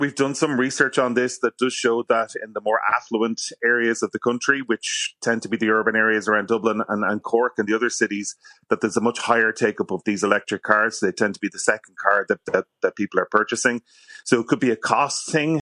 Professor in Transportation